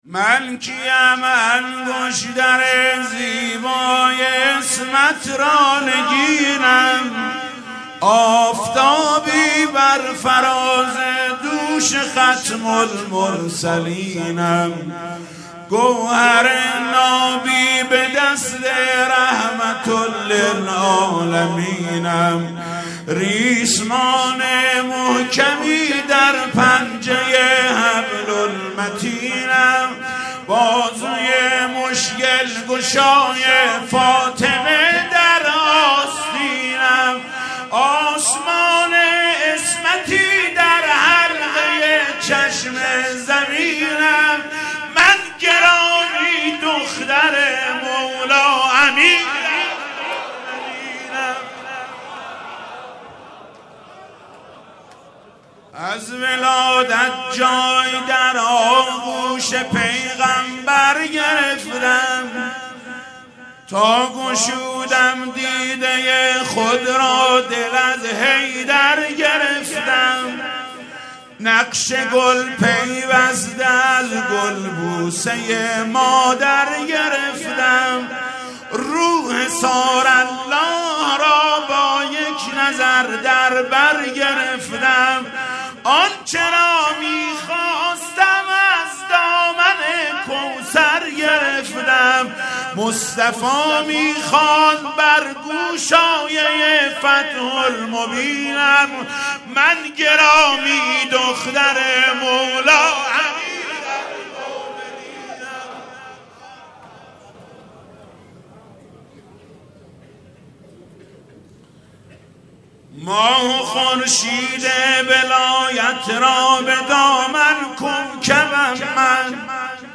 مناسبت : وفات حضرت زینب سلام‌الله‌علیها
مداح : محمود کریمی قالب : روضه شعر خوانی